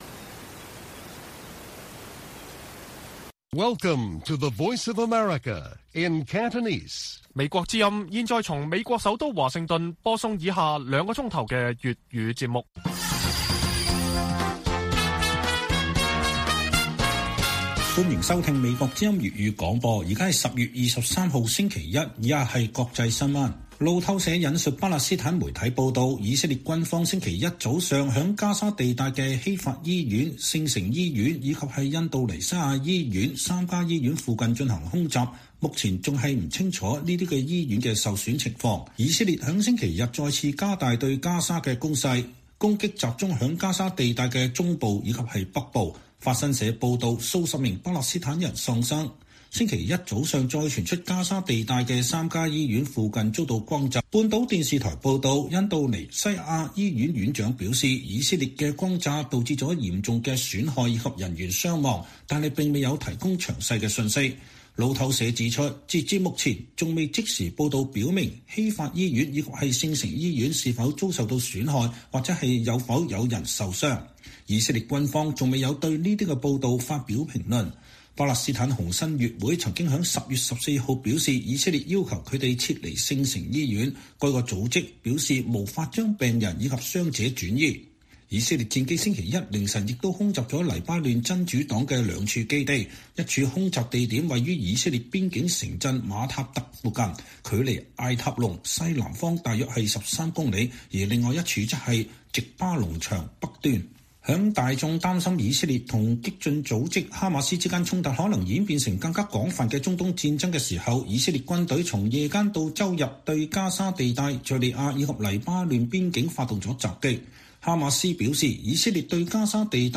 粵語新聞 晚上9-10點: 以色列加大攻勢 加沙三家醫院附近傳遭空襲 傷亡不明